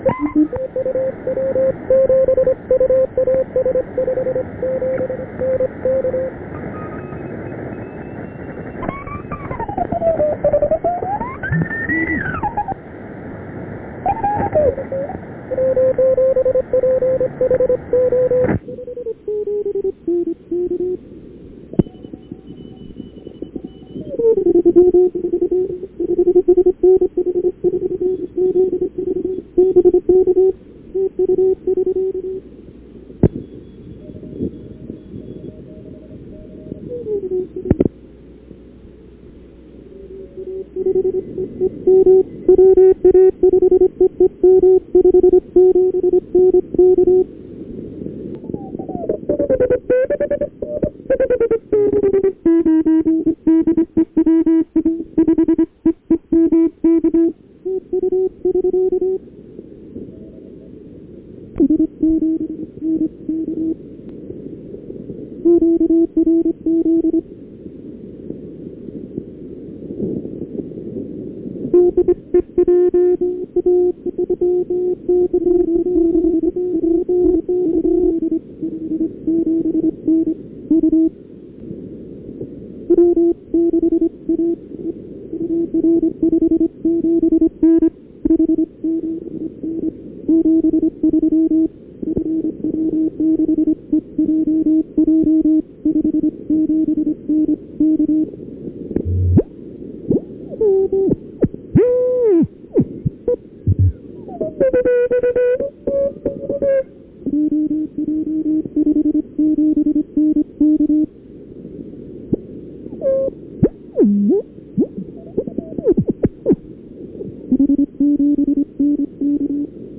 operate from 1S1A in 1973 on Spratly Island on 20 Meter CW from California!